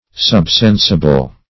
subsensible - definition of subsensible - synonyms, pronunciation, spelling from Free Dictionary
Search Result for " subsensible" : The Collaborative International Dictionary of English v.0.48: Subsensible \Sub*sen"si*ble\, a. Deeper than the reach of the senses.